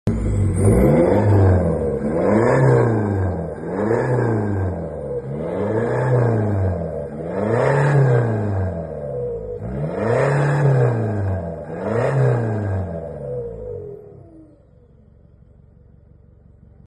FULL EXHAUST DMAX 4JJ3 3.0 sound effects free download